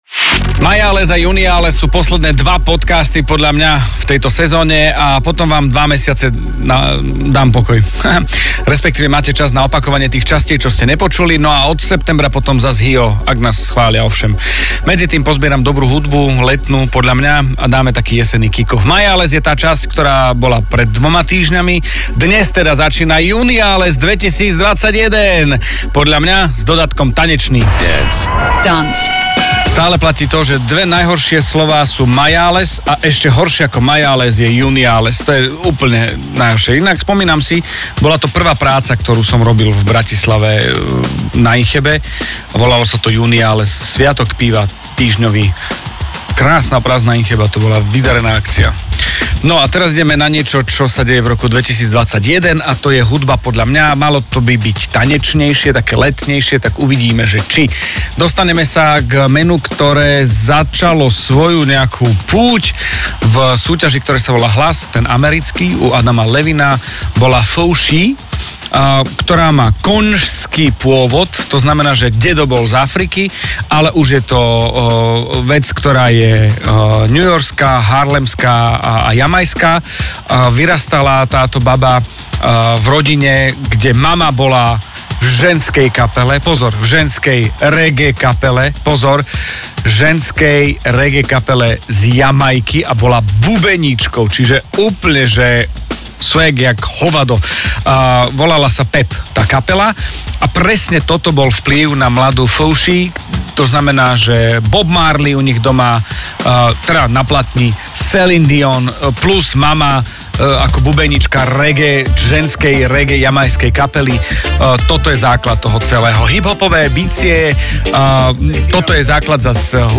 Tento má dodatok "tanečný".